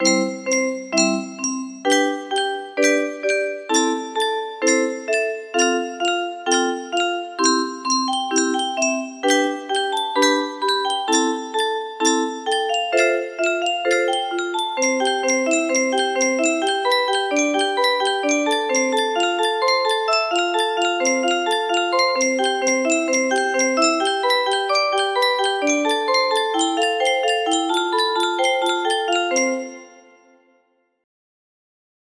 test music box melody
Grand Illusions 30 (F scale)